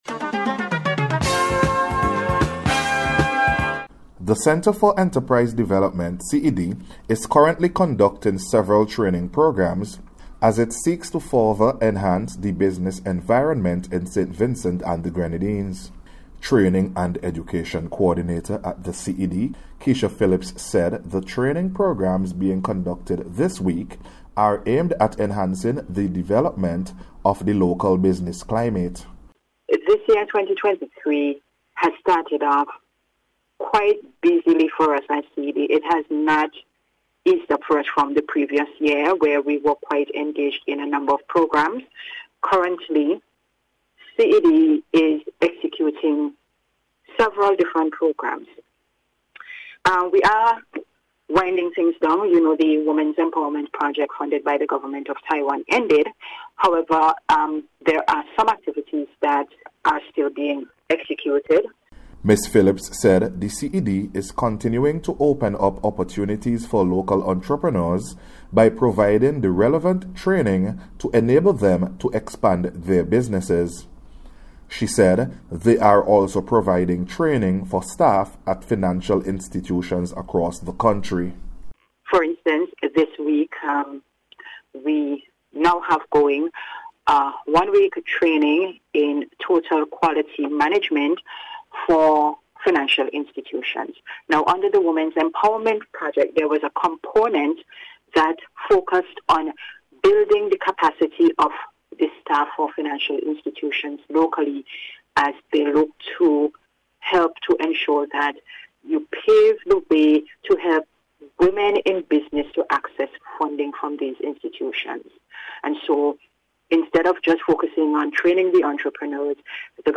CED-WEP-FINAL-PROGRAM-REPORT.mp3